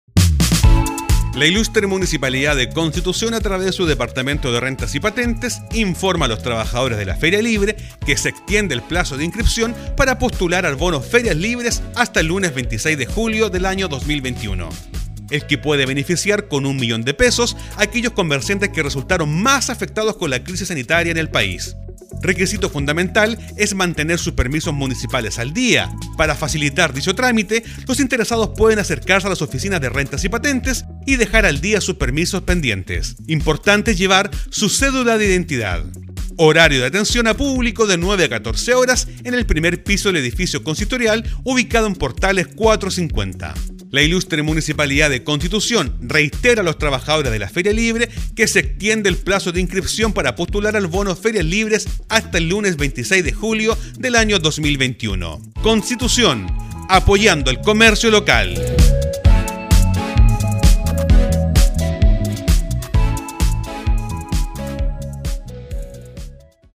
SPOT-BONO-FERIAS-LIBRE-OK-.mp3